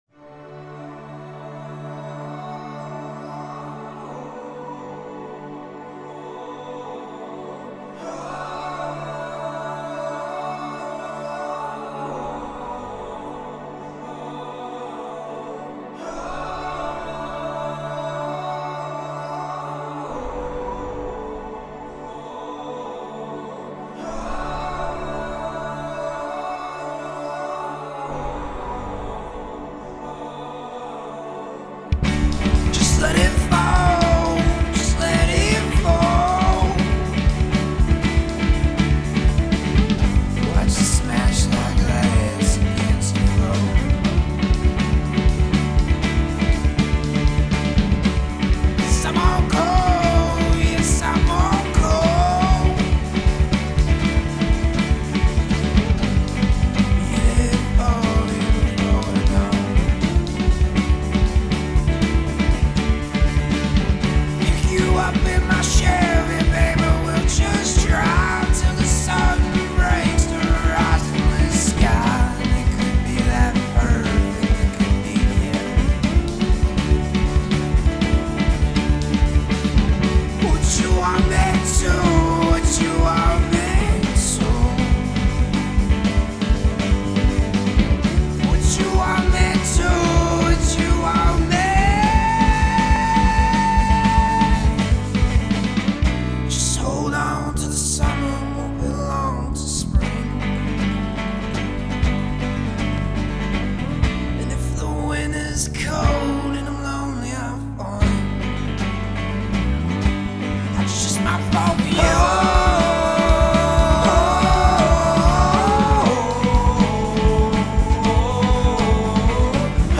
Groupe originaire de Philadelphie